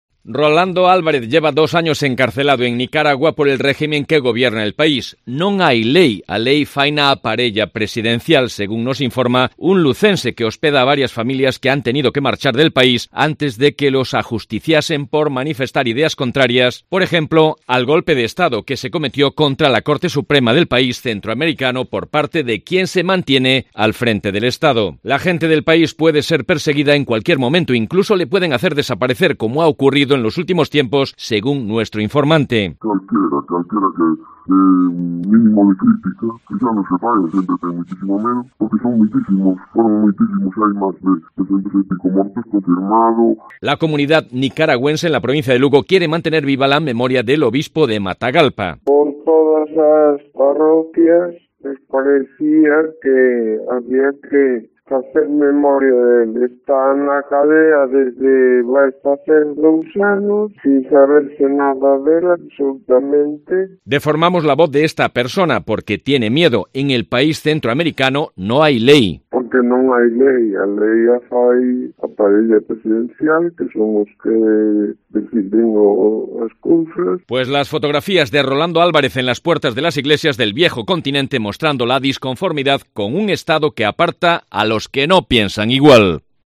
Nuestro informante reconoce que incluso en una ciudad tan distante del lugar del conflicto siente temor y, por eso, manipulamos su voz en nuestro audio para mantener su anonimato.